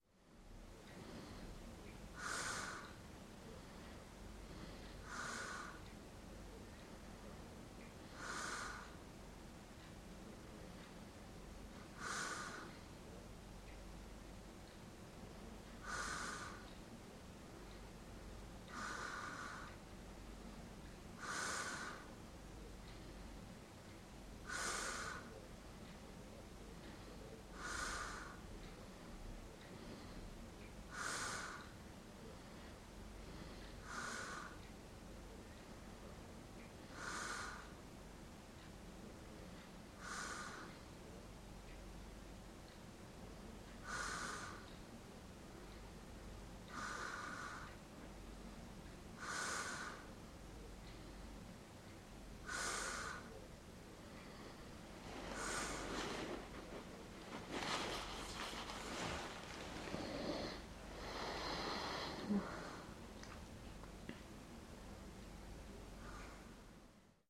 Звуки женского храпа
Тихий женский храп в квартире под мерное тиканье часов